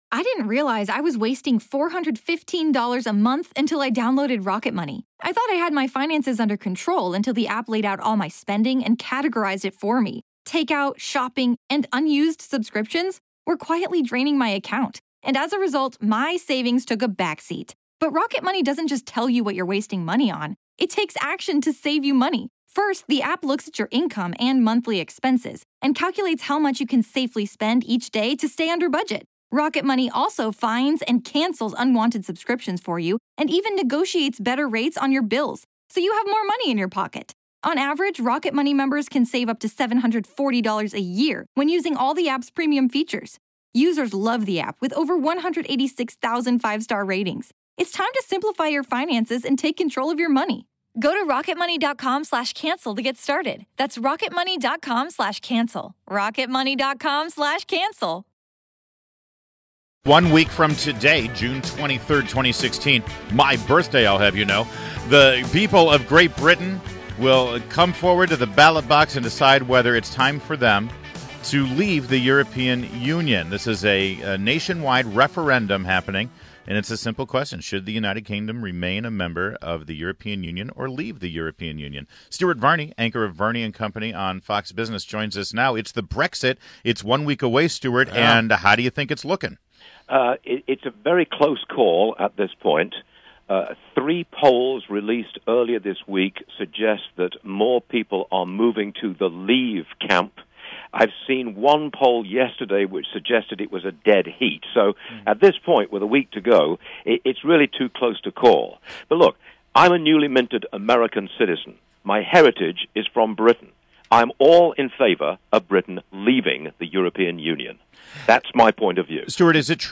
INTERVIEW — STUART VARNEY – Anchor of Varney and Company on Fox Business